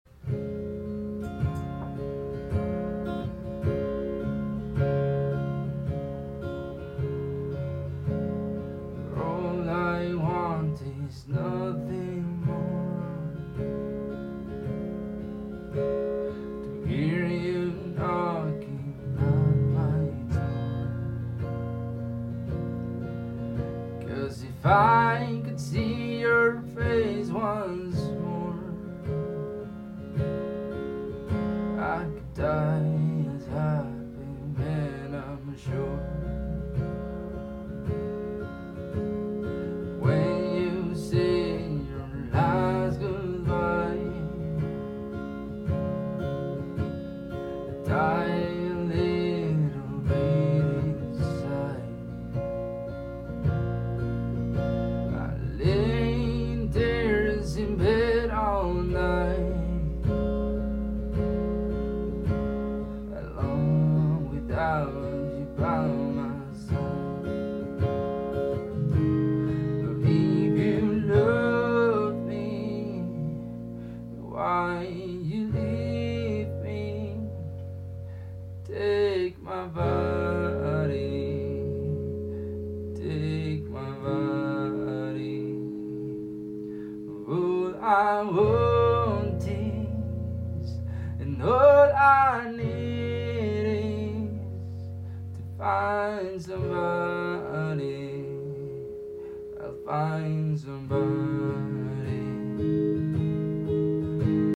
improvisado grabado después del live que hice en mi IG